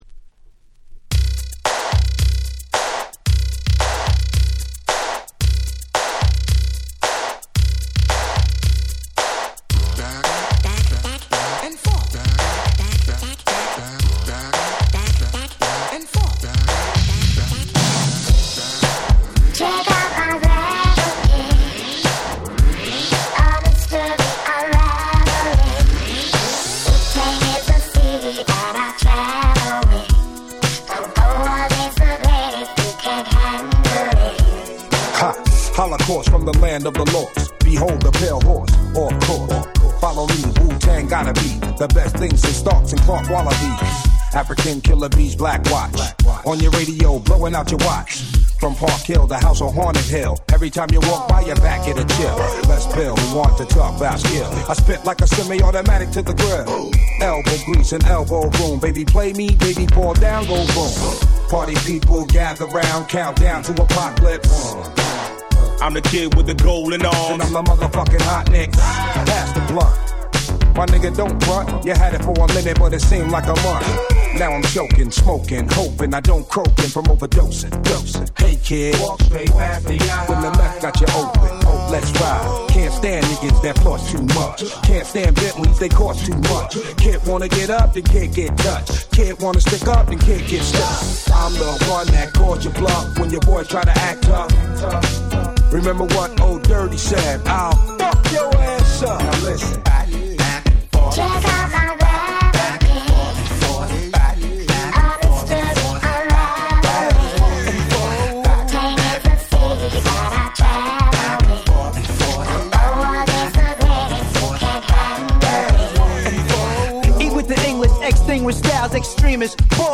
25' Very Nice Remix !!